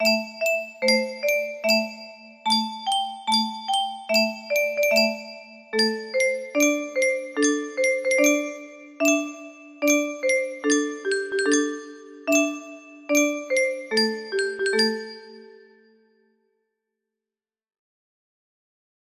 Bangao 2 music box melody